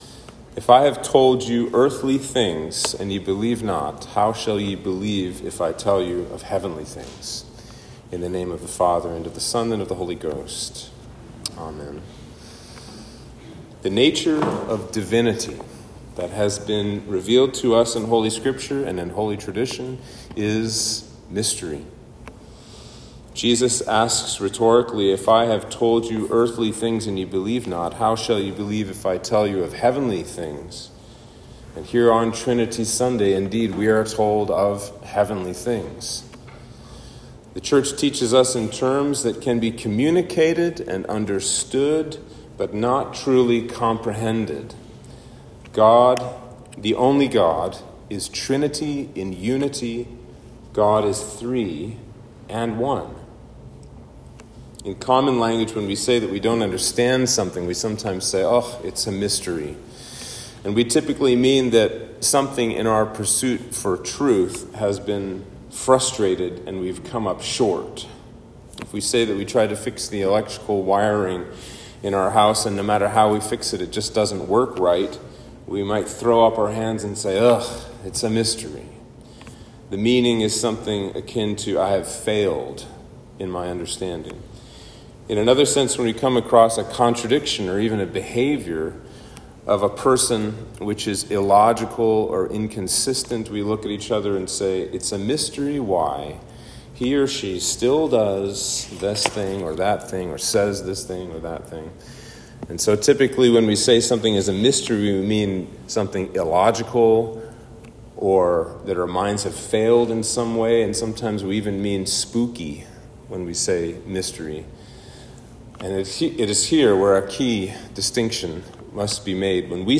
Sermon for Trinity Sunday